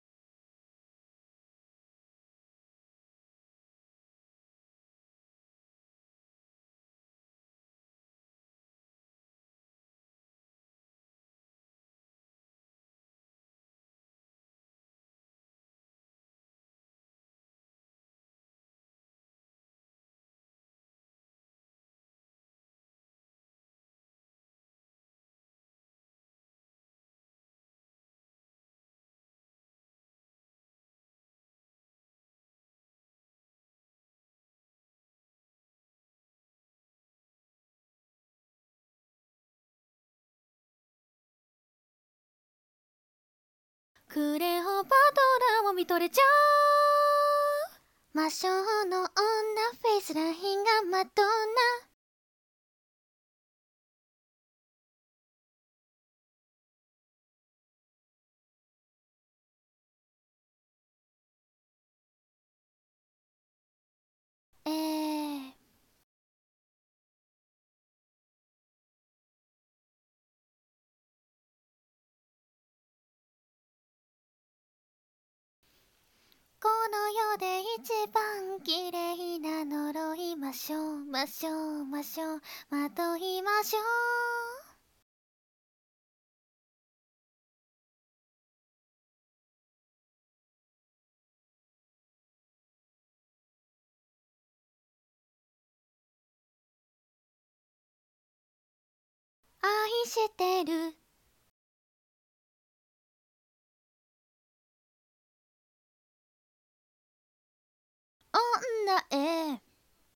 （伴奏なし）を今すぐダウンロード＆リピート再生できます！